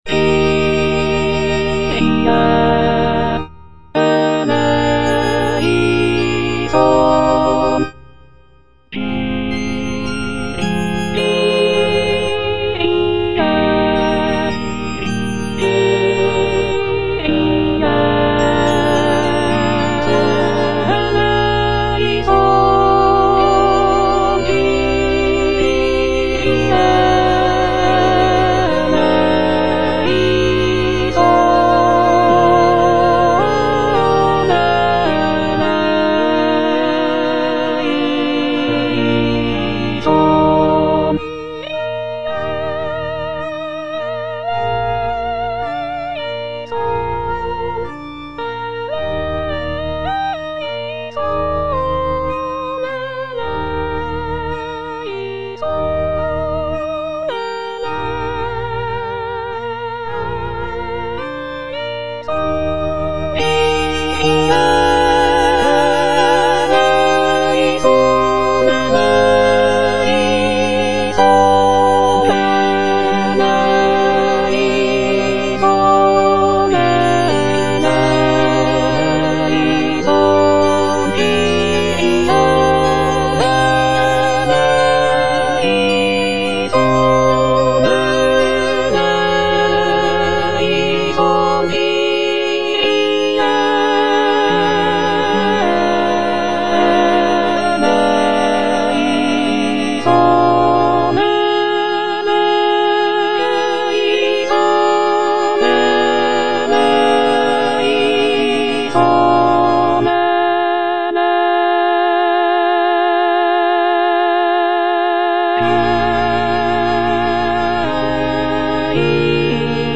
C.M. VON WEBER - MISSA SANCTA NO.1 Kyrie eleison - Alto (Emphasised voice and other voices) Ads stop: auto-stop